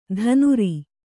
♪ dhanuri